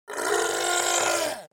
دانلود آهنگ نبرد 9 از افکت صوتی انسان و موجودات زنده
جلوه های صوتی